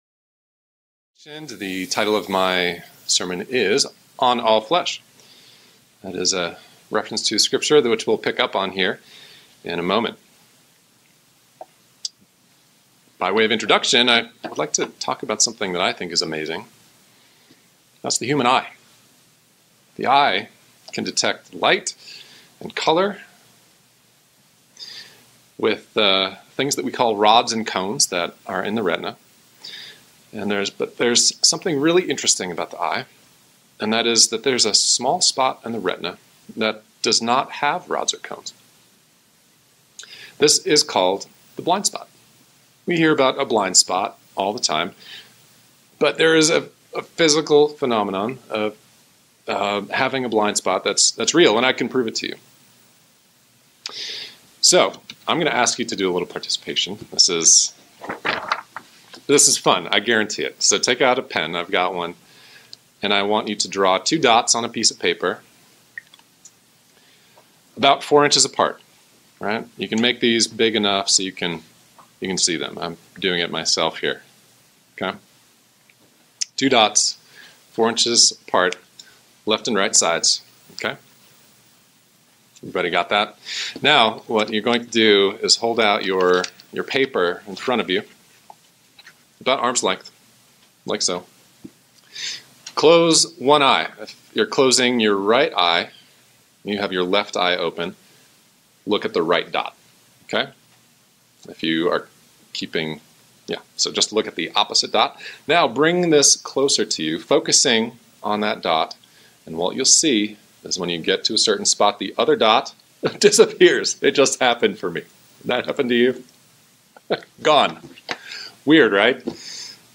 Eight reasons why we’re tested are reviewed in this sermon.